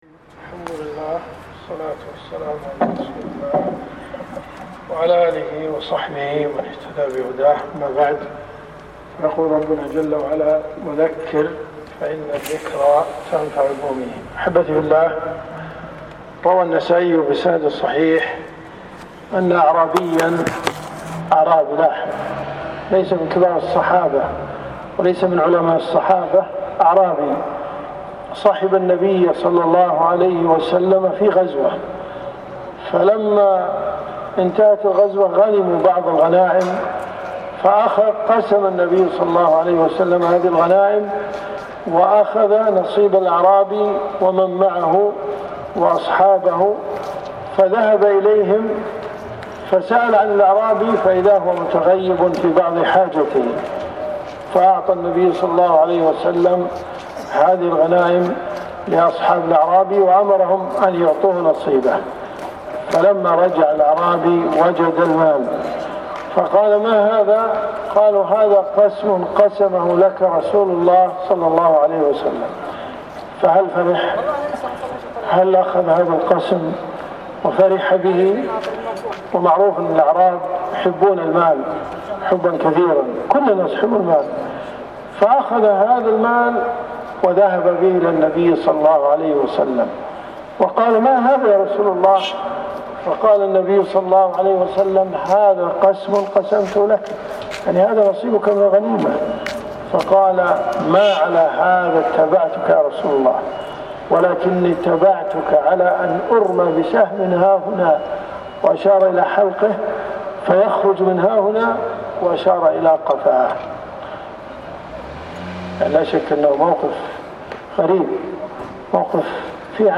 إن تصدق الله يصدقك . جامع أم ابراهيم الذياب .